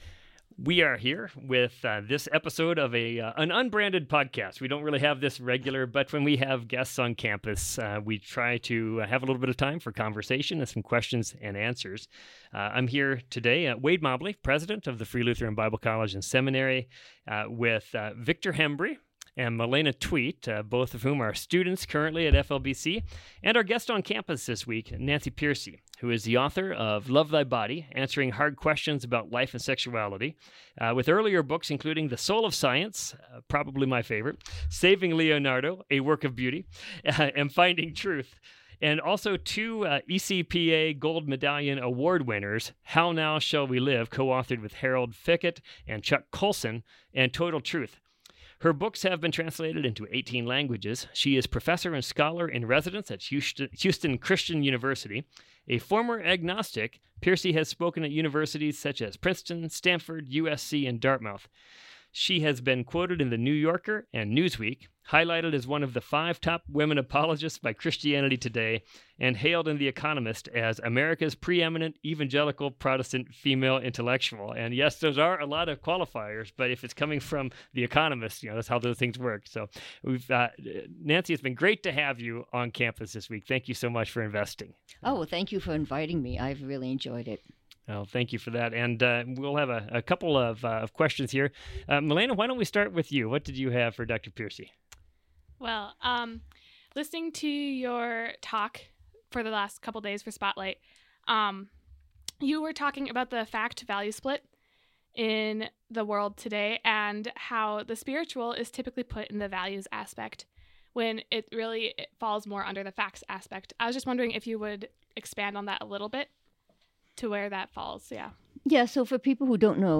Pearcey introduces listeners to the Fact/Value split in the secular worldview, discussing how Christians often fall into the same trap in the form of the Sacred /Secular split. Whether you are new to Christian worldview and philosophy or are a veteran Christian scholar, you are sure to find this conversation informative and refreshing.